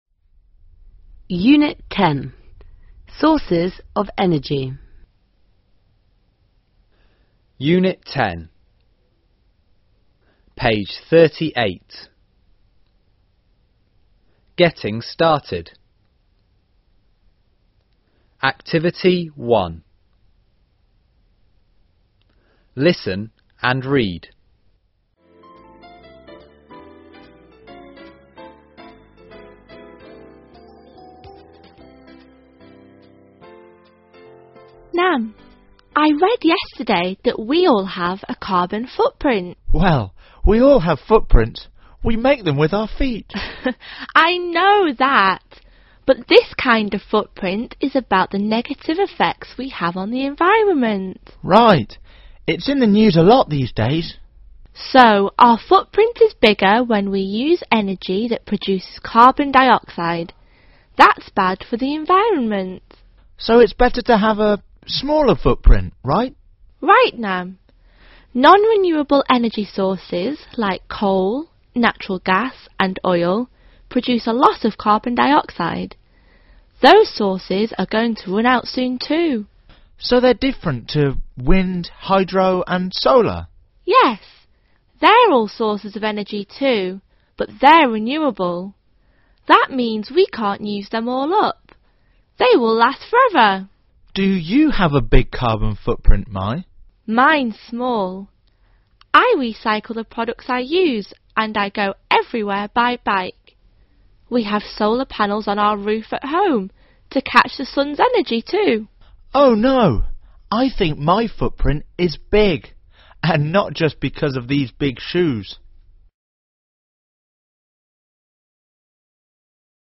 a. Read the conversation again and answer the questions. (Đọc lại bài đàm thoại và trả lời câu hỏi.)